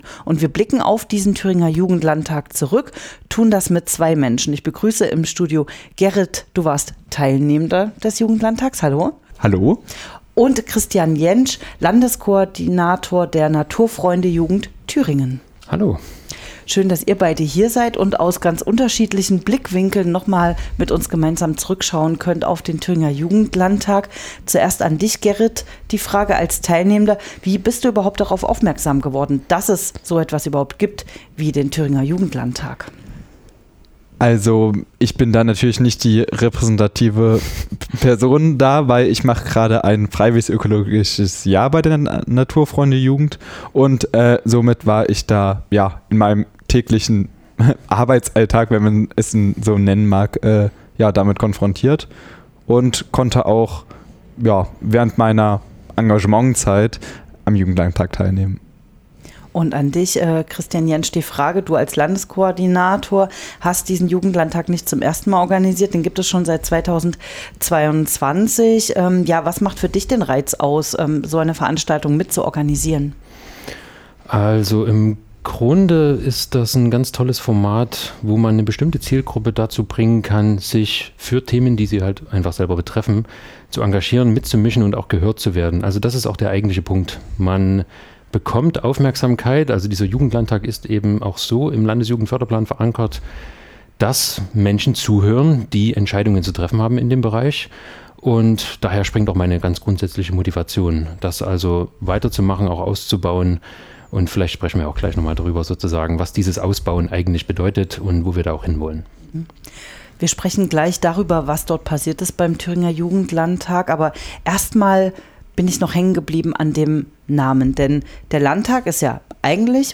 Interview Jugendlandtag 2025_web.mp3